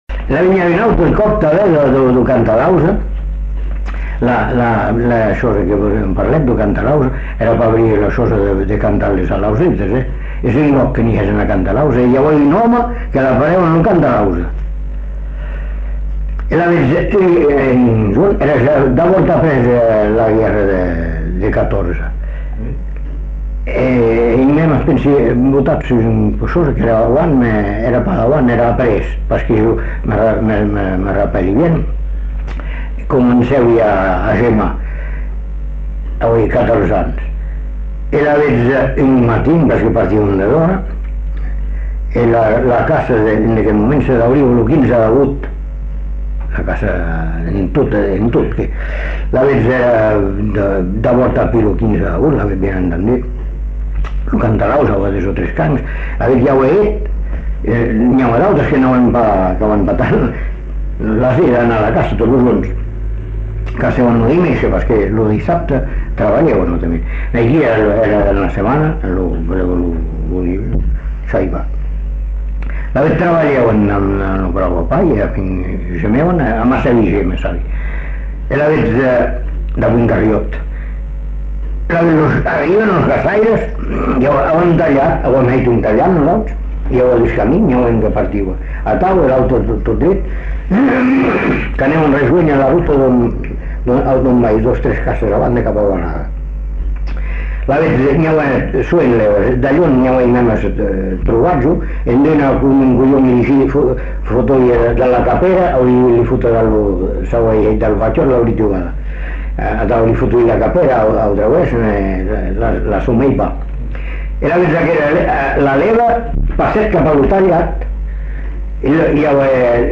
Lieu : Bazas
Genre : conte-légende-récit
Effectif : 1
Type de voix : voix d'homme
Production du son : parlé